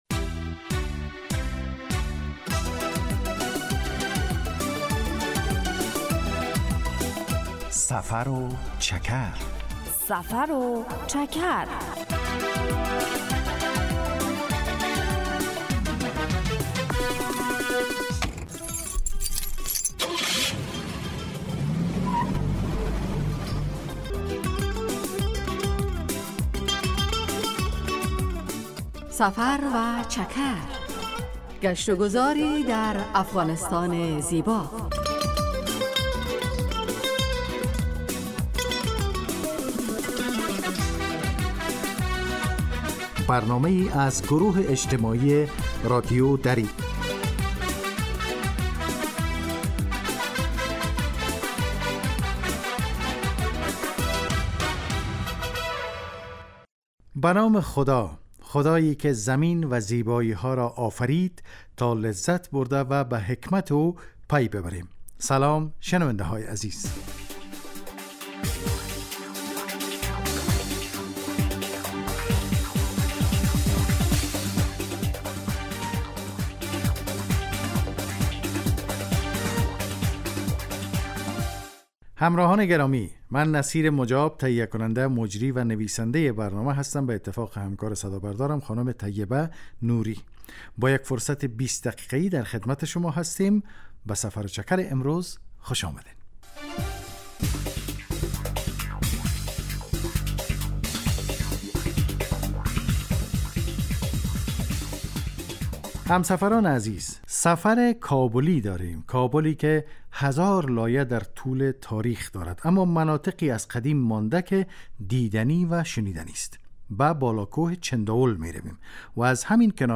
هدف: آشنایی با فرهنگ عامه و جغرافیای شهری و روستایی افغانستان که معلومات مفید را در قالب گزارش و گفتگو های جالب و آهنگ های متناسب تقدیم می کند.